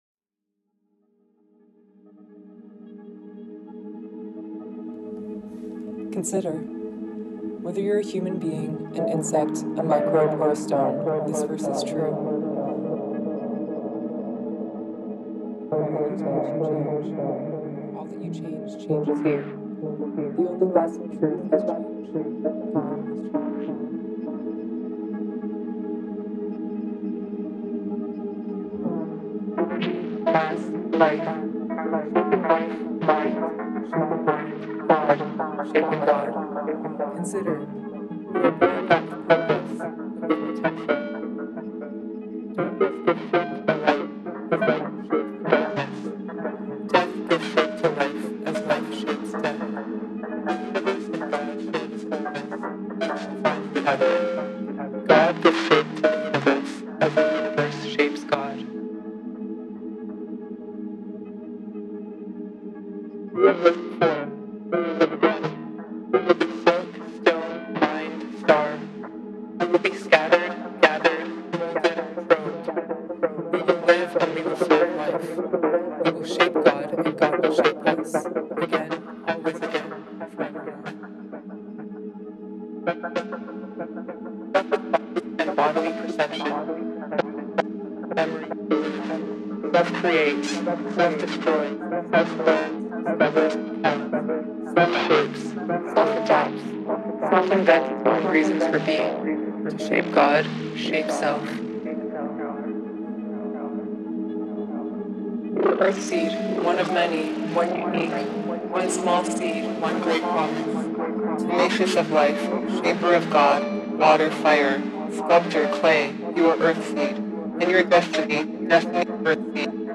Octavia Butler’s Earthseed is a sound work and “reading” of select passages of scriptures from Earthseed, the religion spanning Octavia Butler’s Parable series.
The Matriarch translates, digests, and regurgitates the artist’s voice, producing an ambient soundtrack to presents and futures.